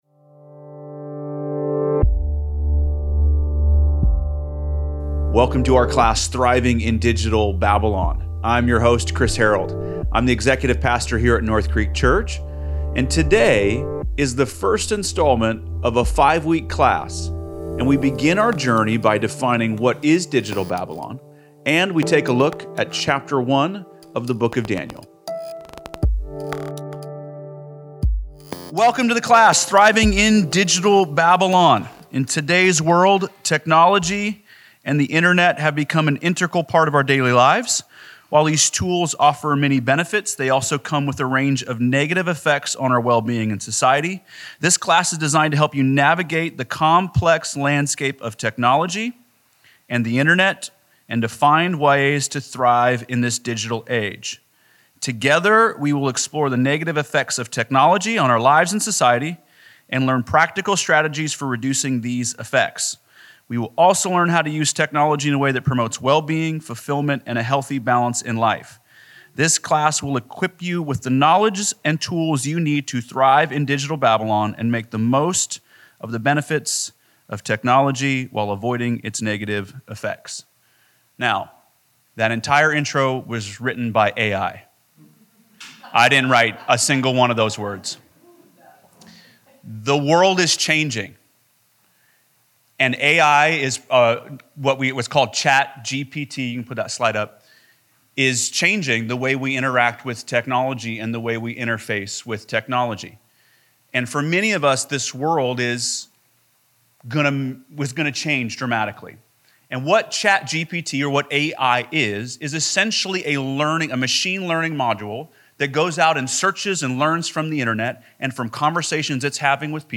Recording from the first session of our 4-week class. This session covers Daniel Chapter 1 and answers the question, "what is Digital Babylon?"